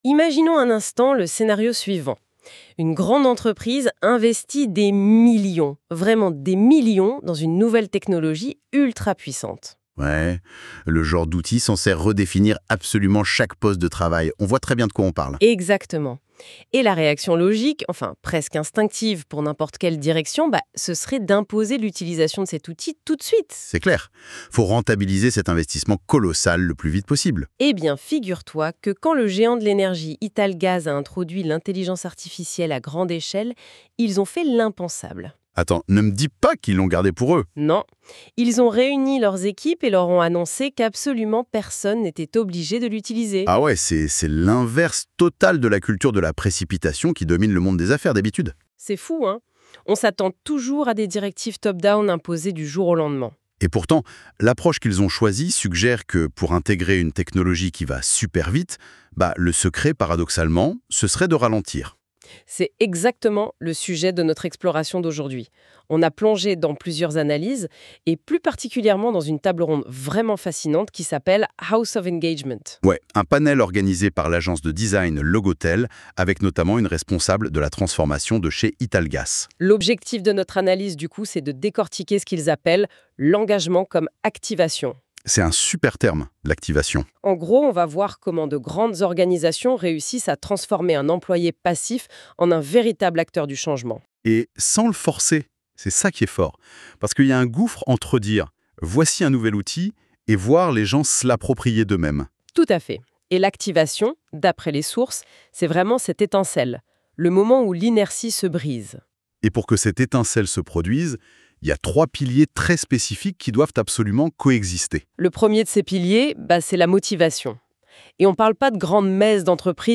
Ici un résumé audio pour saisir l’essentiel de l’épisode :